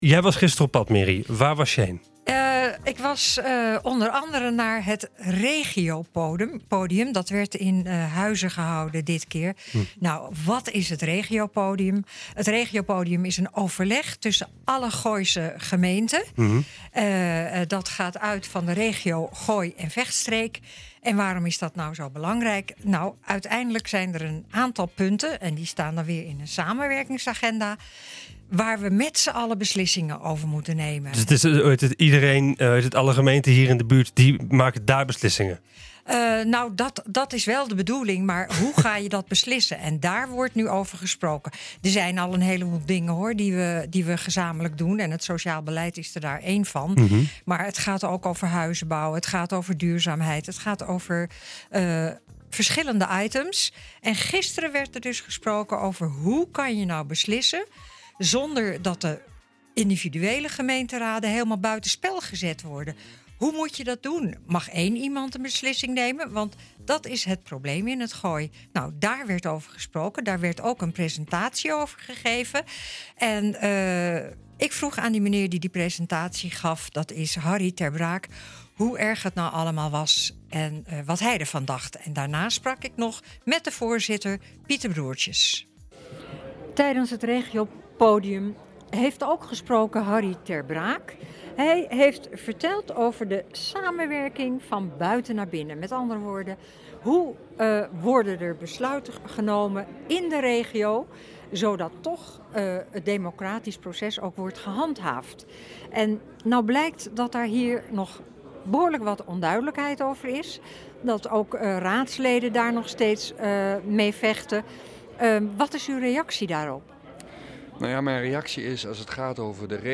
Verslag van het Regiopodium in Huizen waar werd gesproken over de samenwerking tussen de Gooise gemeenten.